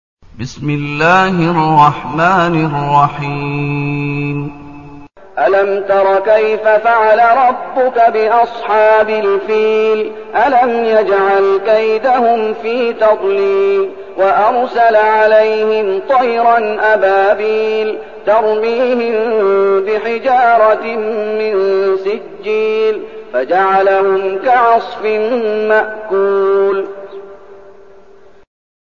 المكان: المسجد النبوي الشيخ: فضيلة الشيخ محمد أيوب فضيلة الشيخ محمد أيوب الفيل